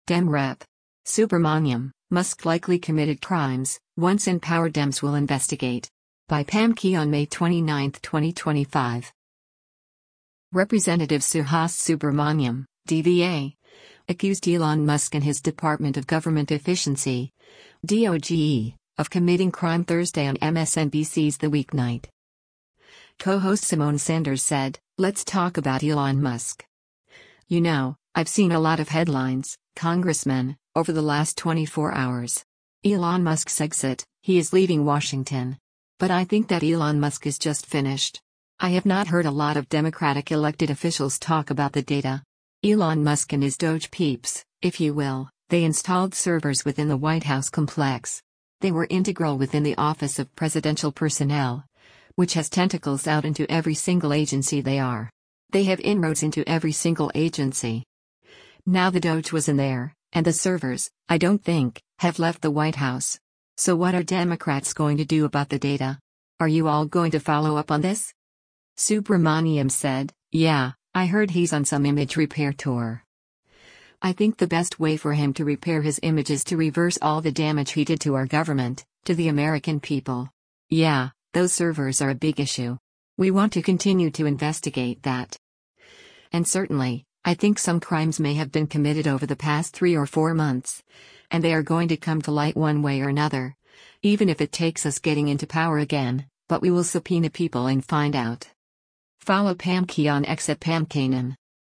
Representative Suhas Subramanyam (D-VA) accused Elon Musk and his Department of Government Efficiency (DOGE) of committing crime Thursday on MSNBC’s “The Weeknight.”